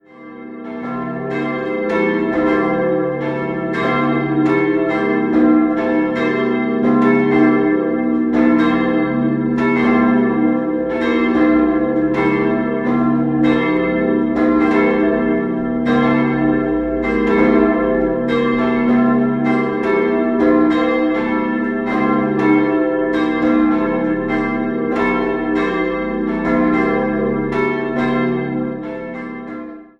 Nach der Wende fanden langjährige, große Sanierungsmaßnahmen am und im Gebäude statt. 3-stimmiges C-Dur-Geläute: c'-e'-g' Die Glocken wurden im Jahr 1920 von der Gießerei Schilling&Lattermann hergestellt.